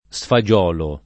sfagiolo [ S fa J0 lo ]